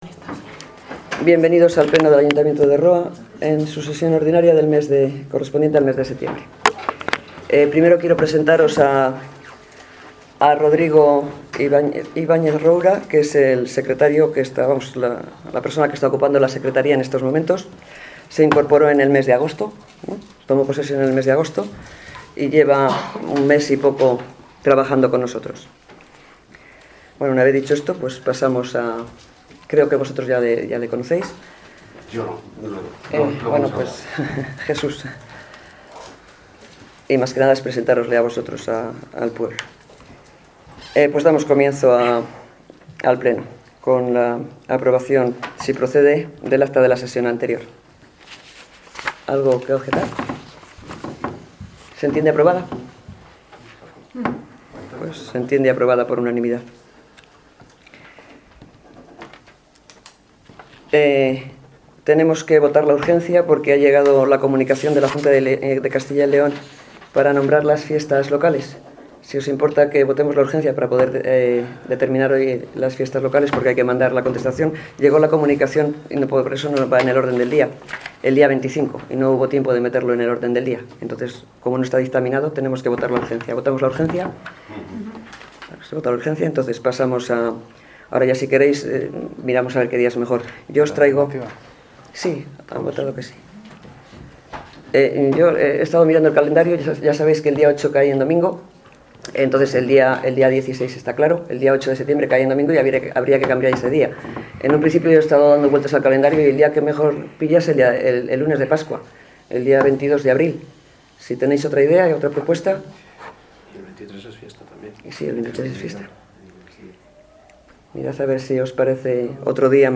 A continuación, adjuntamos el audio del pleno del 27 de septiembre de 2018.